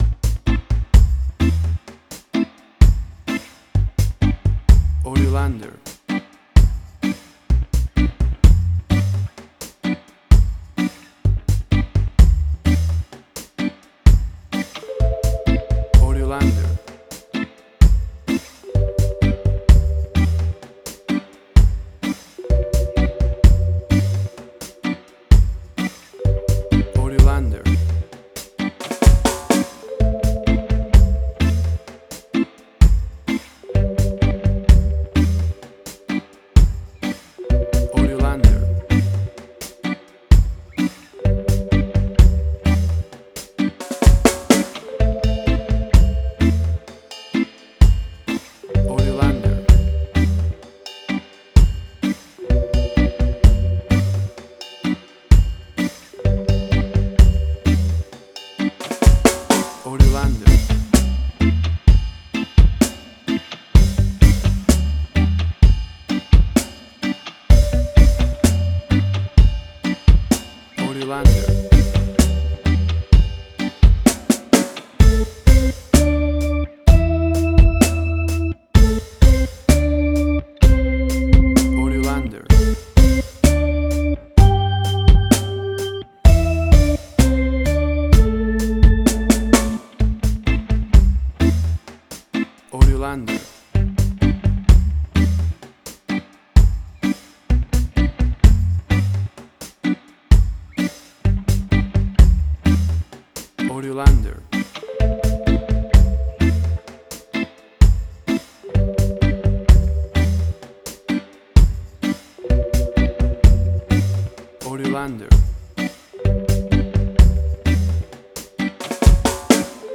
Reggae caribbean Dub Roots, old school reggae
Tempo (BPM): 64